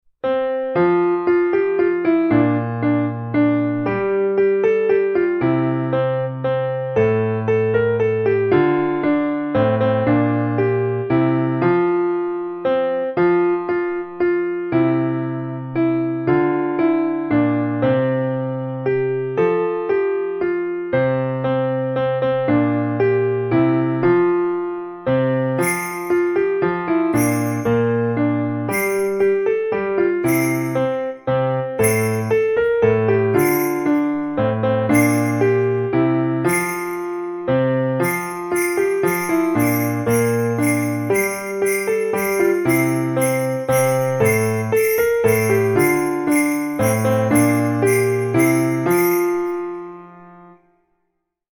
Genres: Classical Music
Tempo: 116 bpm